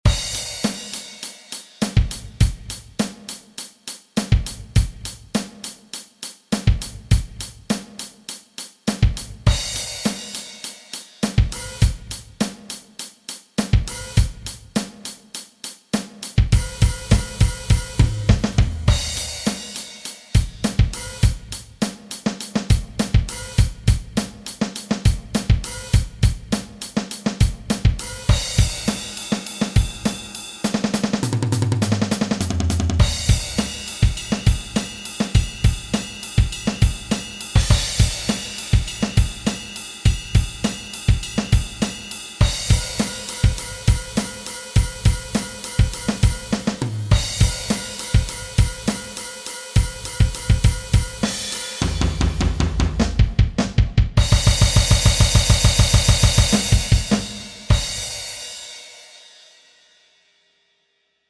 Die Noten und Hörbeispiele in schnell und langsam gibt es unten.